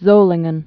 (zōlĭng-ən)